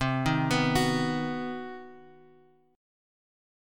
C Minor Major 7th Flat 5th